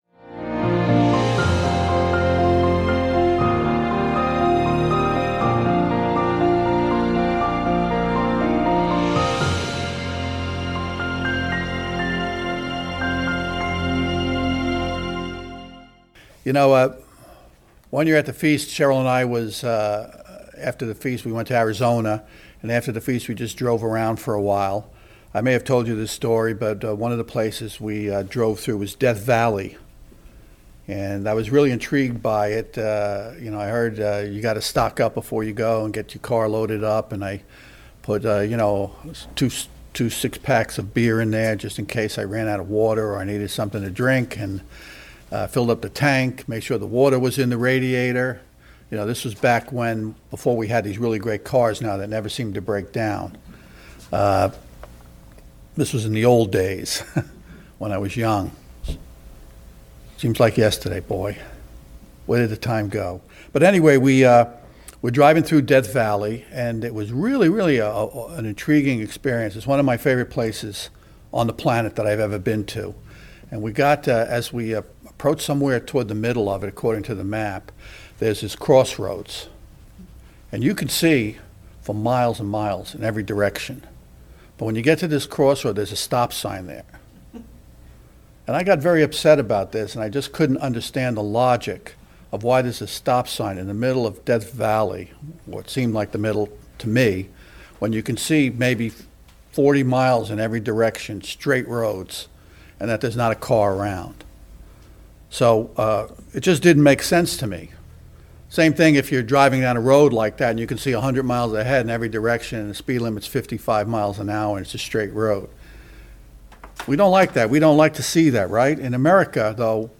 Sermons
Given in Charlotte, NC